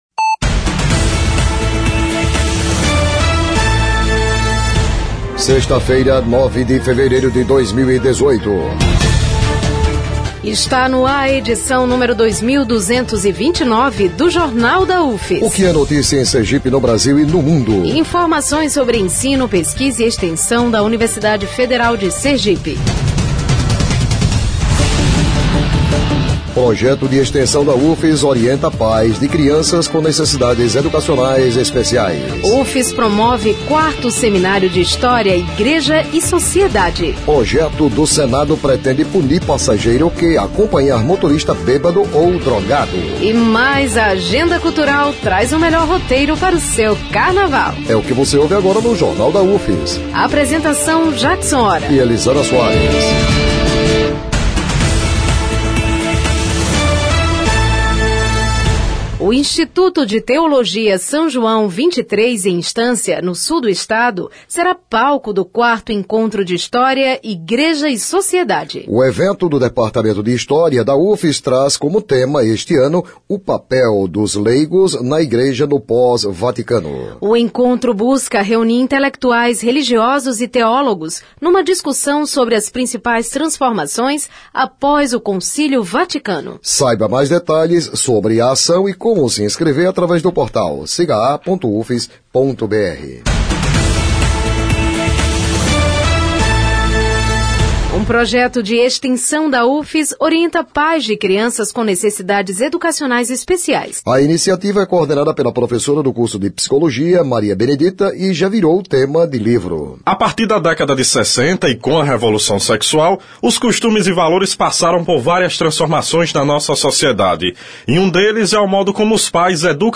O noticiário vai ao ar às 11h na Rádio UFS FM 92.1, com reprises às 17h e 22h.